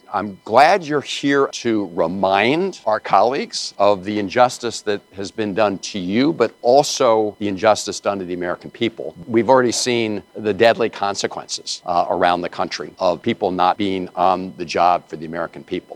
The Trump Administration continues to scale back the size of government, but lawmakers like Senator Chris Van Hollen continue to work on behalf of the fired workers. The senator met for the fifth time with workers earlier this week…